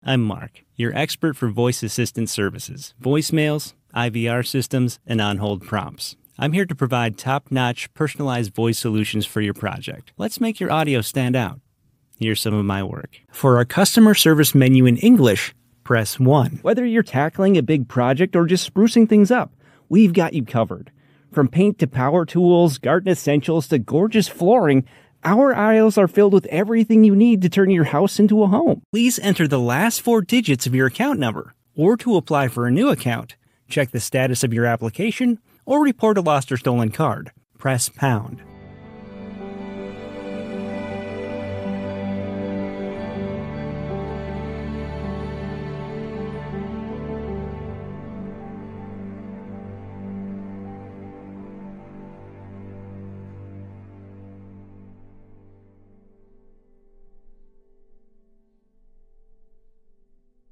Male
Yng Adult (18-29), Adult (30-50)
Phone Greetings / On Hold
Phone Messaging Demo